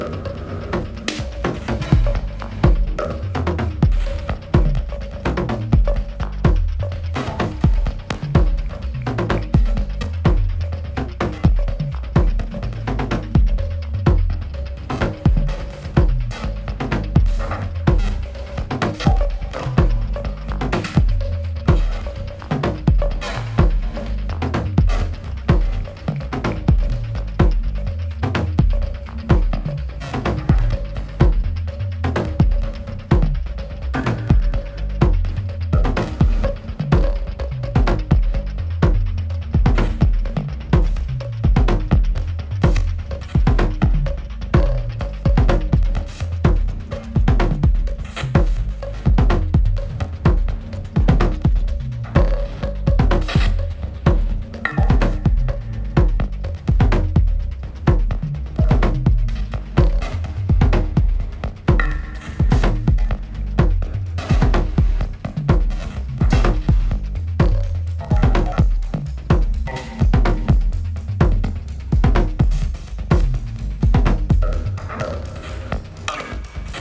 パーカッションで補強しながらブロークンなリズムを刻む
一際研ぎ澄まされた構成で成立したサイケデリック・トライバル・テクノ